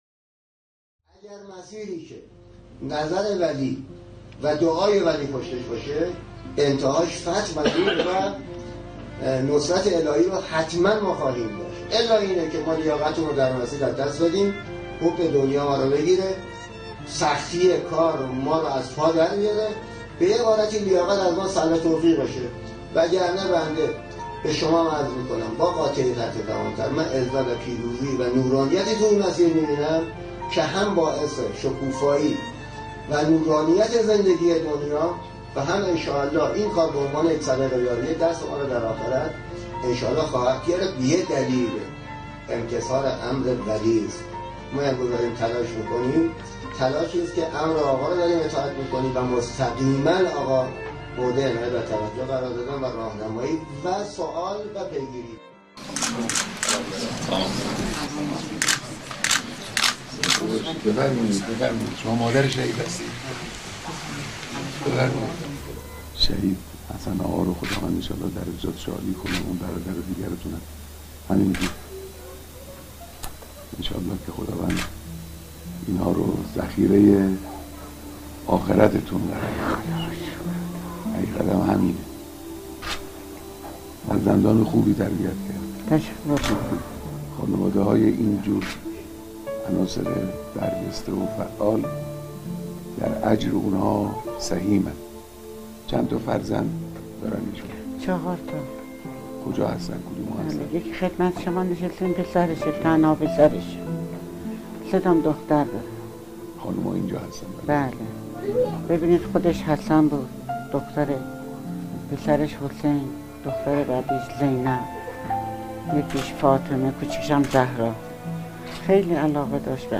صدای شهید طهرانی مقدم با عنوان مدیر بلند همت، عزت و نورانیت در این مسیر است که هم باعث نورانیت این دنیا هم دستمان را در آخرت خواهد گرفت چون که امتثال امر ولی است.